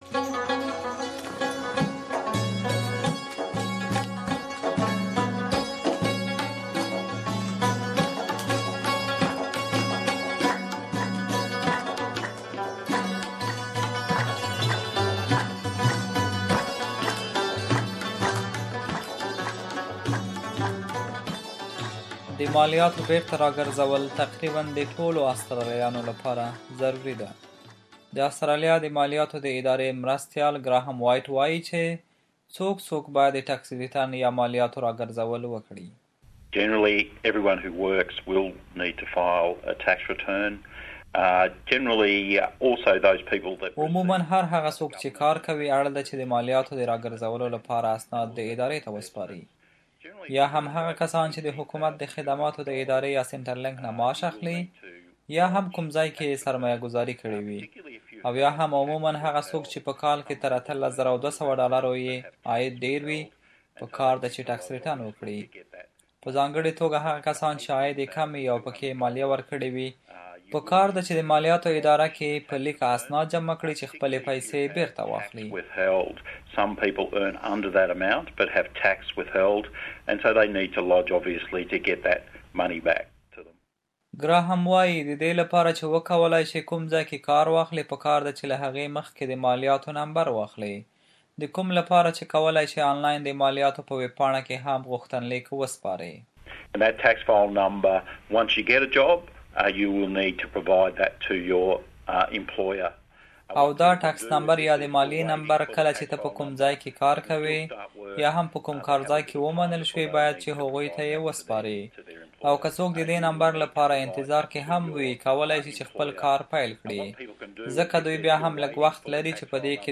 Tax returns are needed for almost all earners in Australia. Australian Tax Office Assistant Commissioner Graham Whyte explains who has to lodge a tax return.